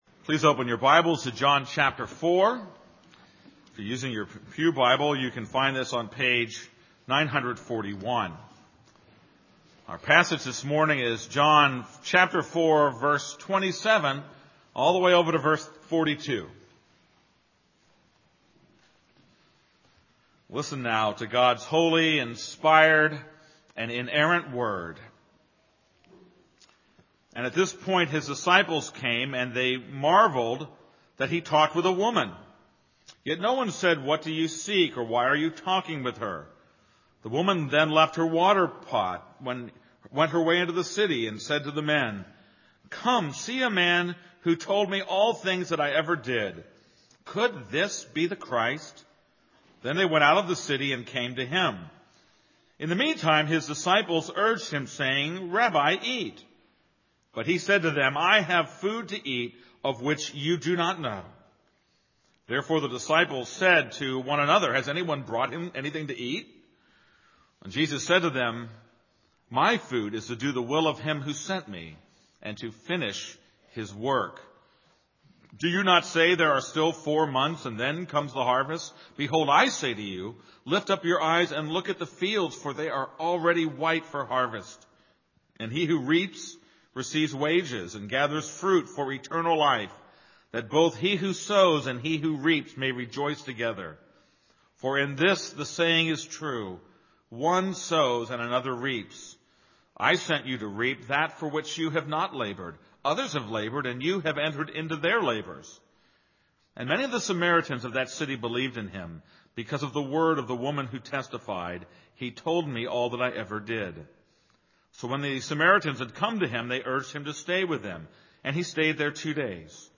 This is a sermon on John 4:27-30.